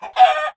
chickenhurt1.ogg